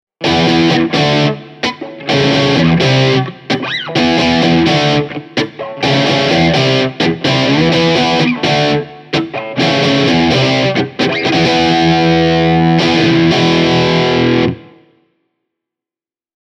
Classic Overdrive vie Blackstarin soundia klassiseen 1970- ja 80-luvun Marshall-alueeseen – soundi on rouhea ja kiinteä.
Tällaisia soundeja irtosi Blackstar HT Club 40 -kombosta, kun soitin sen läpi Hamer USA Studio Custom ja Gibson Les Paul Junior kitarani:
Gibson Les Paul Junior – Classic Overdrive
gibson-les-paul-junior-e28093-classic-overdrive.mp3